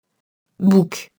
bouc [buk]